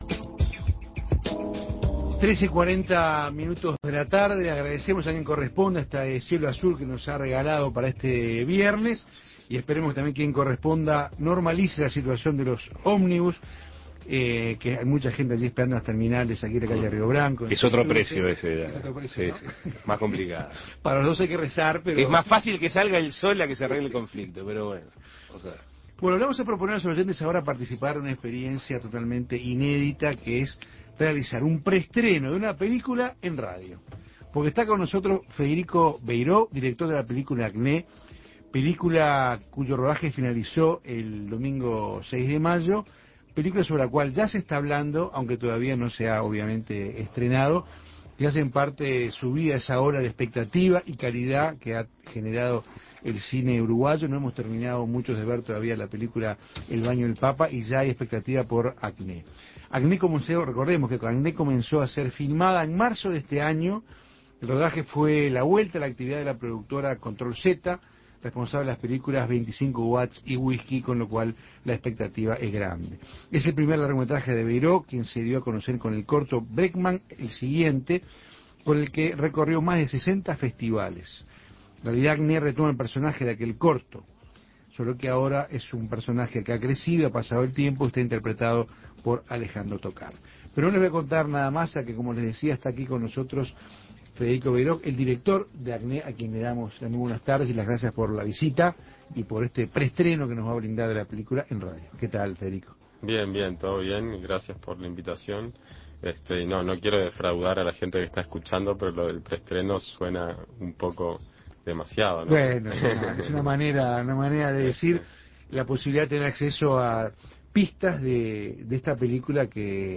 Federico Veiroj, su director, contó sobre la película, que tiene como centro a un chico de 13 años, que se encuentra en su despertar sexual, su familia y amigos.